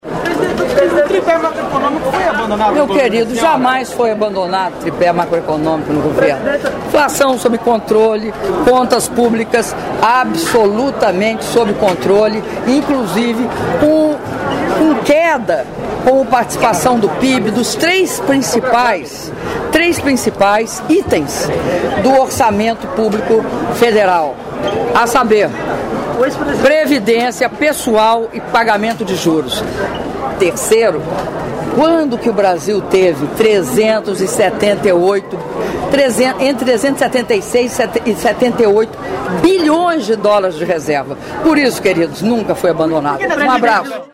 Áudio da entrevista concedida pela Presidenta da República, Dilma Rousseff, após cerimônia de anúncio de investimentos do PAC Mobilidade Urbana e assinatura dos contratos de metrô e de corredores - Salvador/BA (44s)